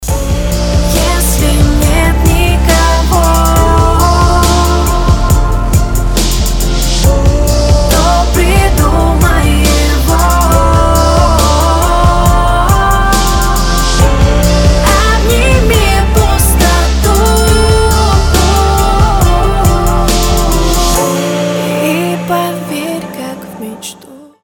• Качество: 320, Stereo
поп
женский вокал
мелодичные
спокойные